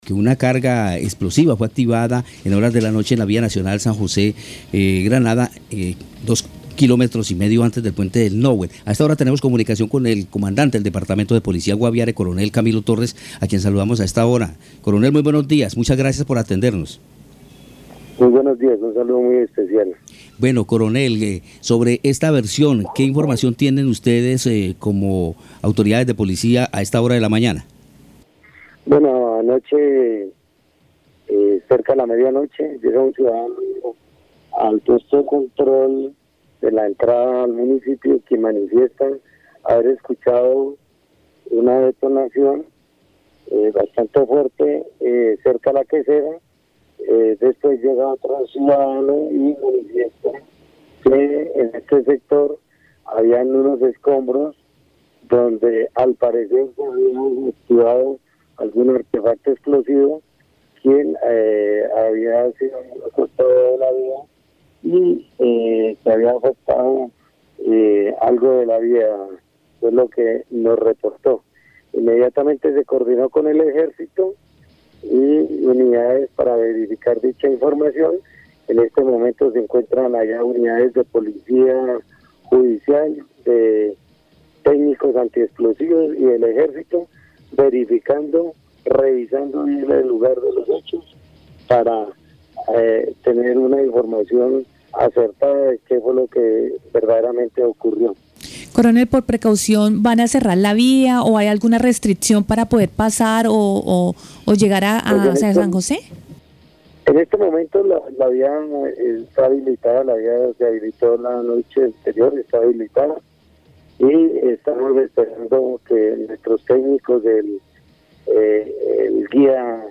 Escuche al Coronel Camilo Torres, comandante del Departamento de Policía Guaviare.